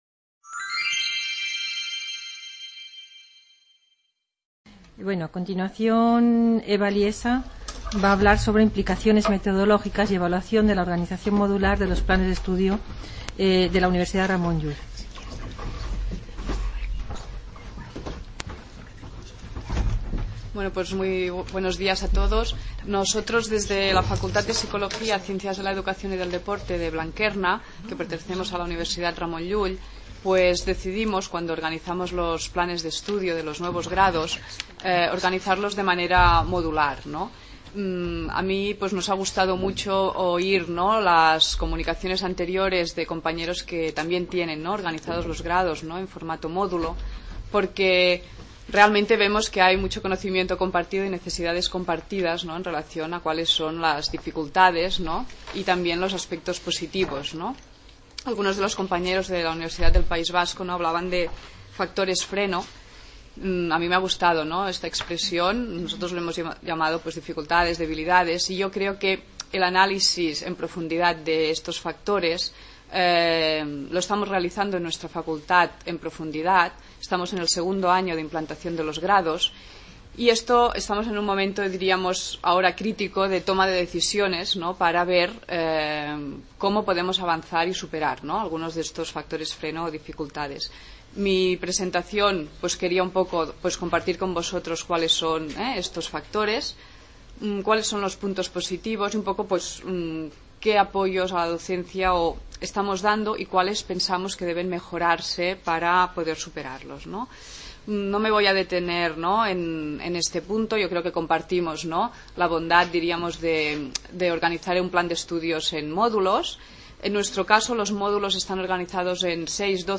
Reunion, debate, coloquio...